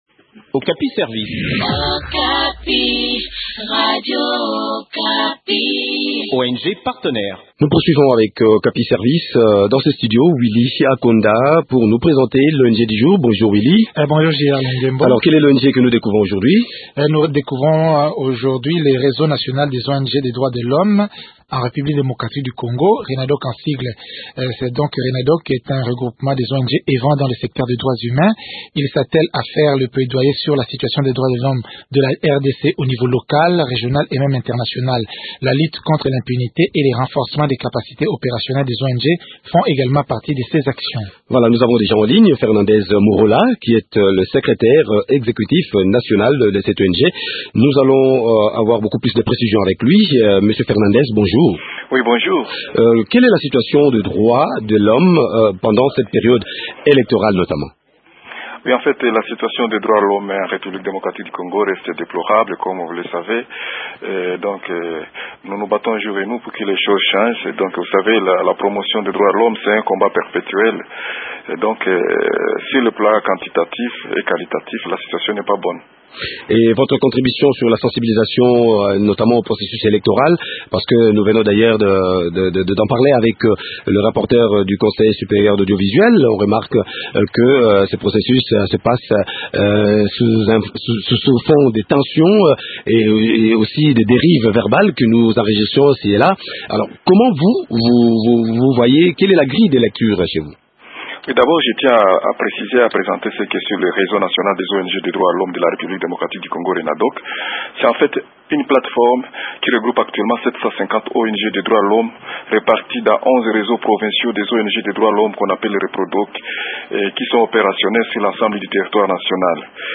Le point sur les activités de cette structure dans un entretien